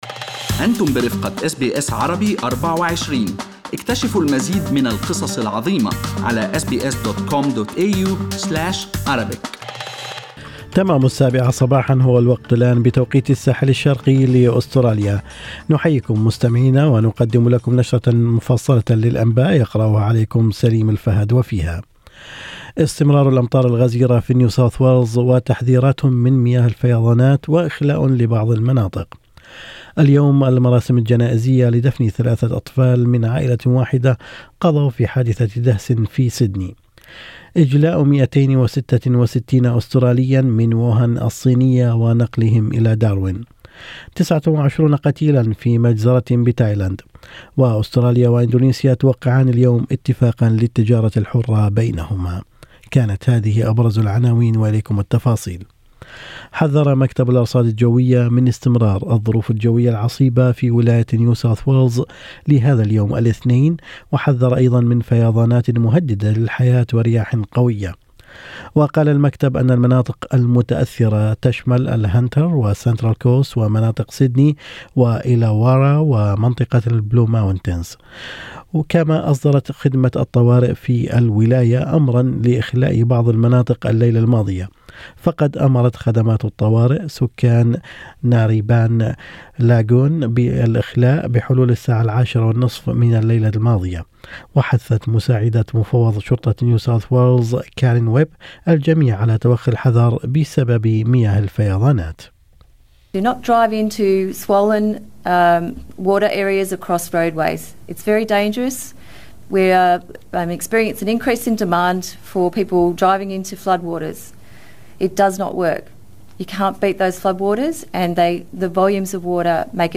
Arabic News Bulletin Source: SBS Arabic24